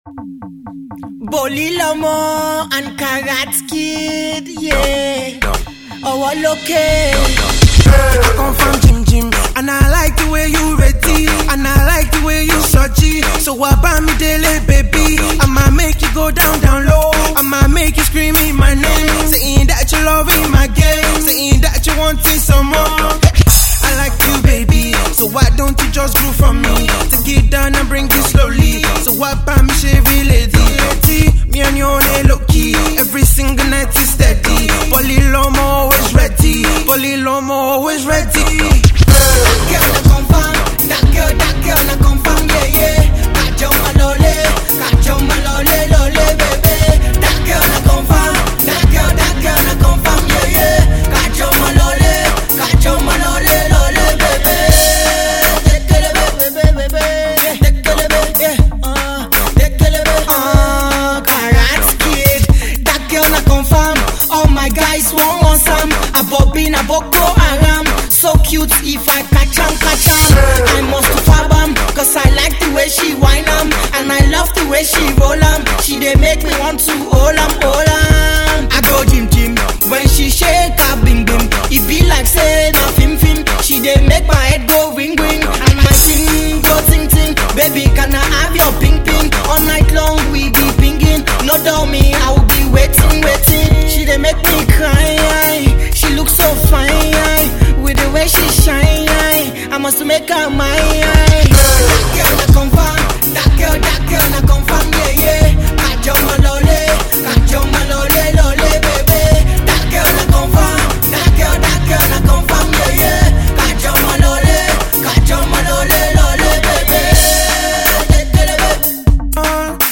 party song
is sure to make you move your body